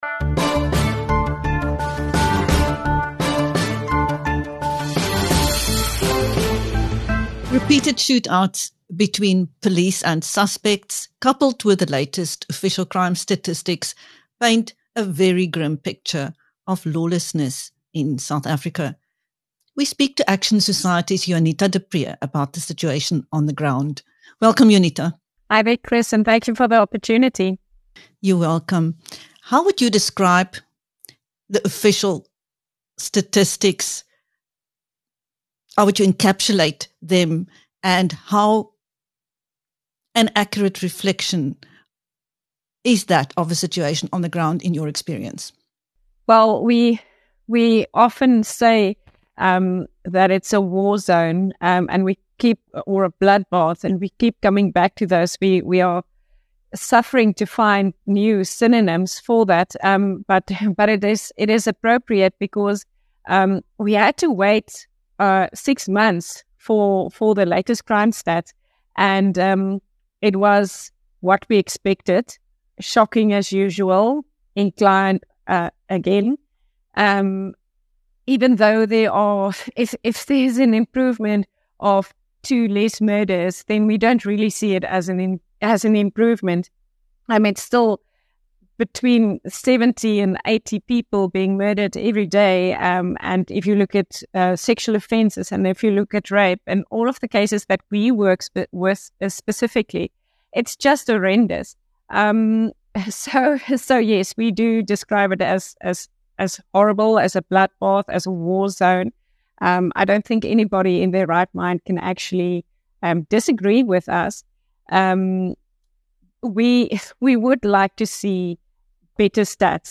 Action Society, the former home of crime fighter Ian Cameron, who is now the Chair of the Portfolio Committee on Police in Parliament, is continuing its fight for justice for victims and their families. In this interview